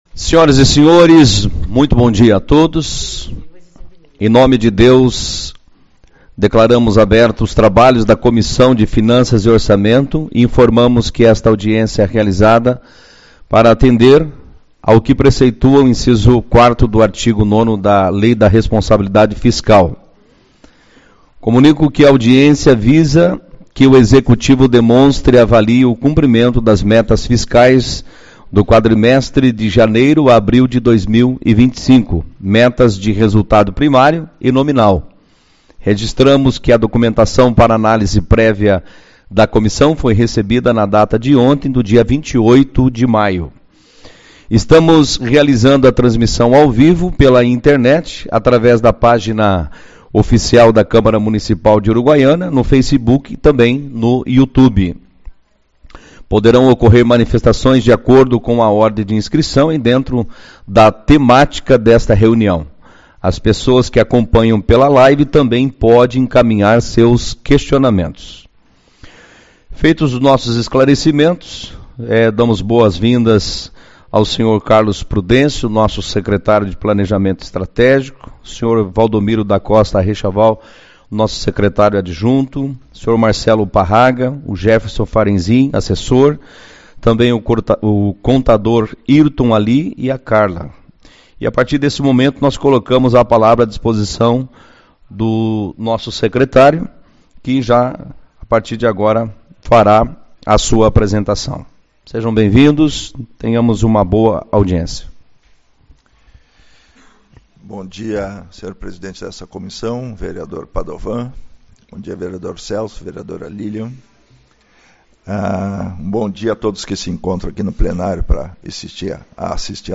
28/05 - Audiência Pública-Metas Fiscais 1º Quadrimestre